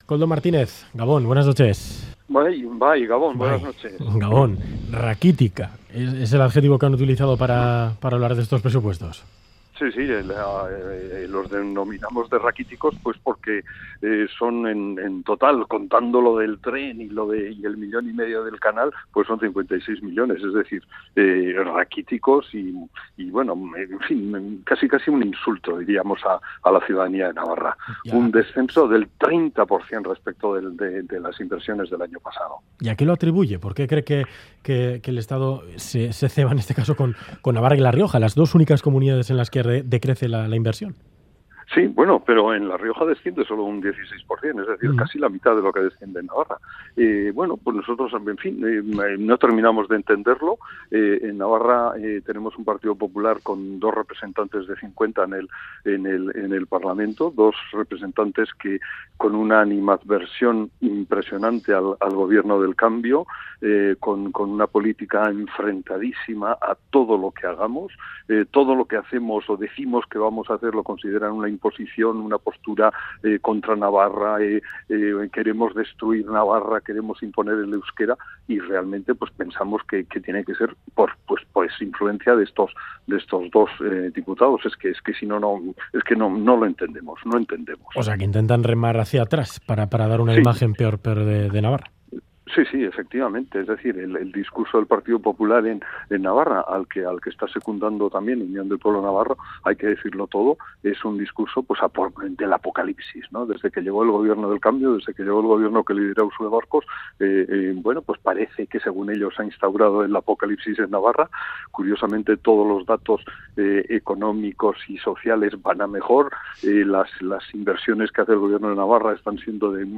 Audio: Entrevista en Ganbara a Koldo Martínez, portavoz de Geroa Bai, tras conocerse el descenso en la inversión prevista para Navarra en los Presupuestos Generales del Estado.